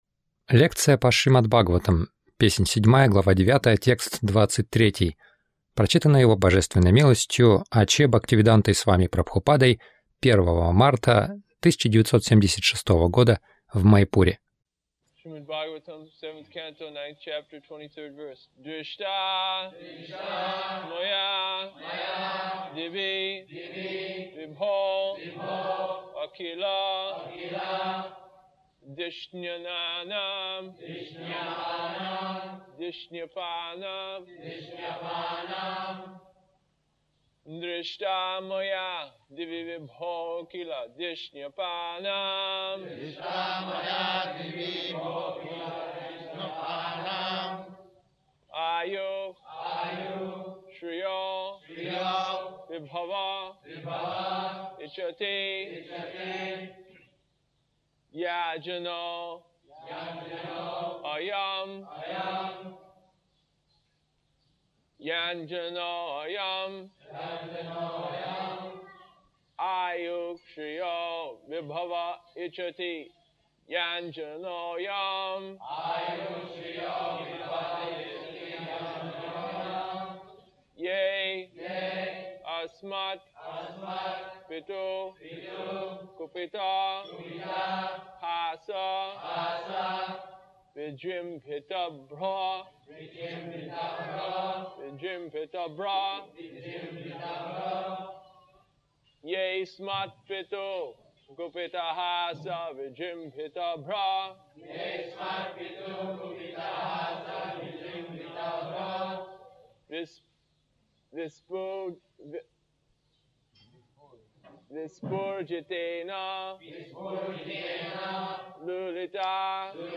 Милость Прабхупады Аудиолекции и книги 01.03.1976 Шримад Бхагаватам | Маяпур ШБ 07.09.23 — Не возлагайте надежды на материальное Загрузка...